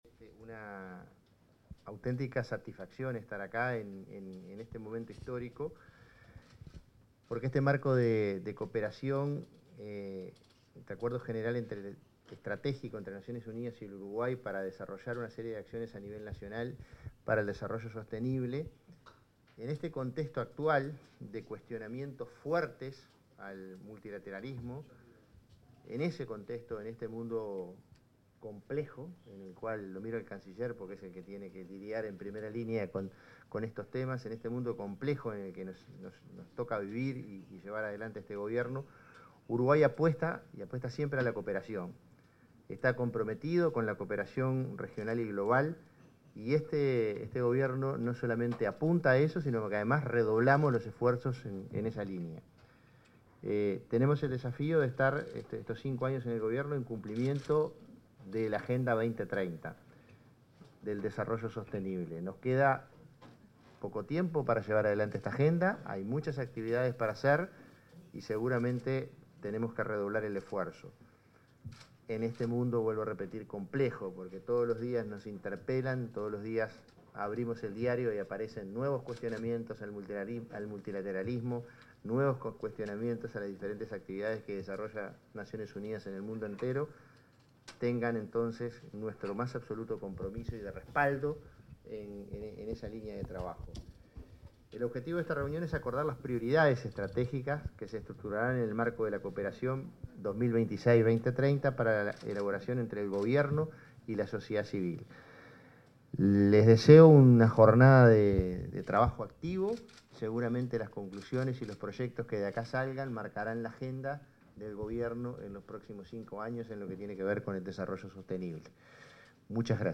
Palabras del prosecretario de Presidencia, Jorge Díaz, y el canciller Mario Lubetkin
Palabras del prosecretario de Presidencia, Jorge Díaz, y el canciller Mario Lubetkin 10/06/2025 Compartir Facebook X Copiar enlace WhatsApp LinkedIn El prosecretario de la Presidencia, Jorge Díaz y el canciller de la República, Mario Lubetkin, se expresaron en la apertura de una reunión con integrantes del Sistema de las Naciones Unidas, en la que se definió el marco estratégico para la cooperación en el período 2026-2030.